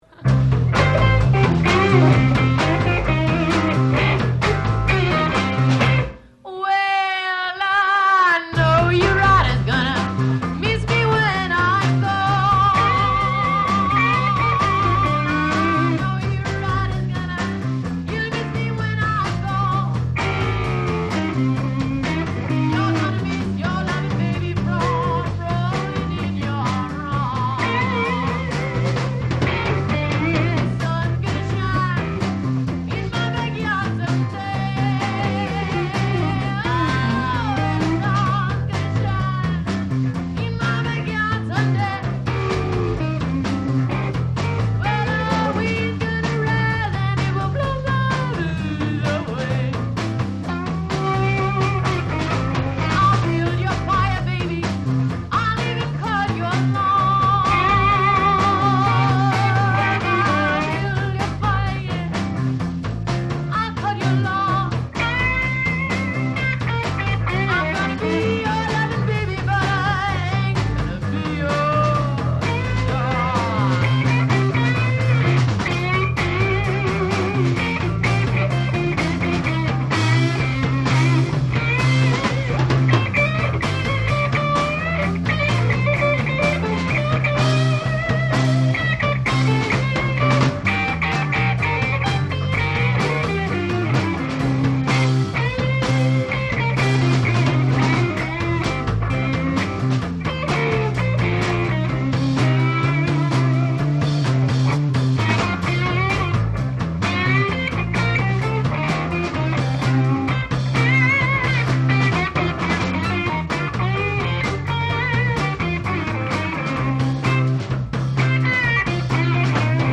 live at Ladd's, Portsmouth, NH
lead guitar